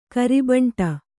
♪ kari baṇṭa